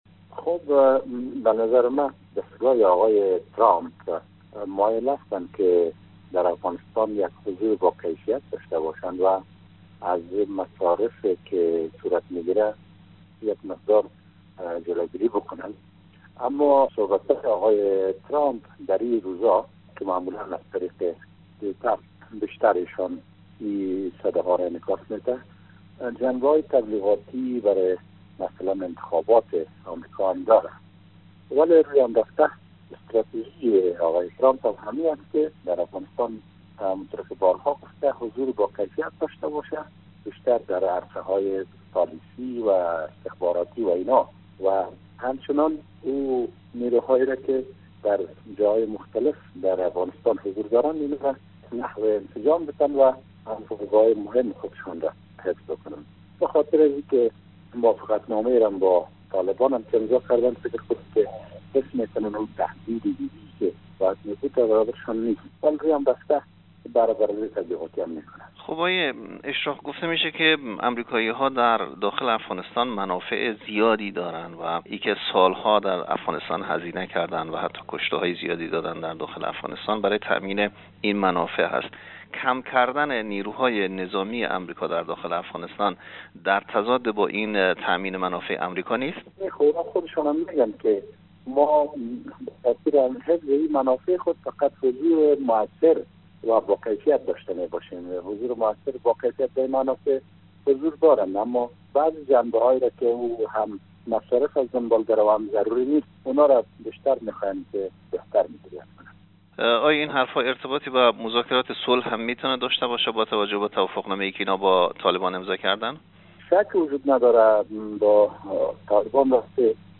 کارشناس و پژوهشگر افغان
در گفت و گو با خبرنگار رادیو دری